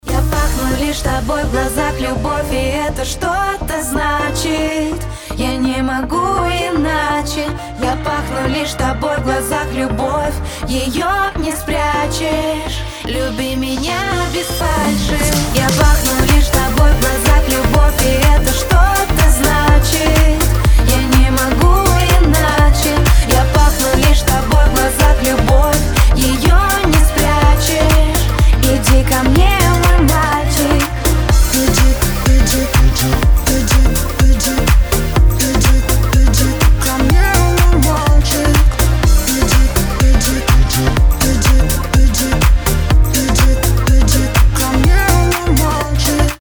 • Качество: 320, Stereo
поп
dance
романтика
vocal